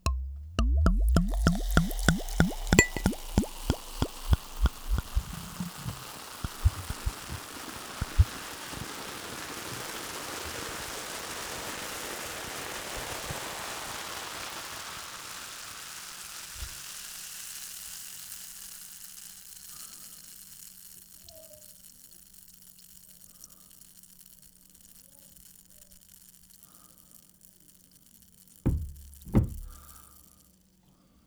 Original creative-commons licensed sounds for DJ's and music producers, recorded with high quality studio microphones.
pouring champagne long.wav
pouring_champagne_long_u0l.wav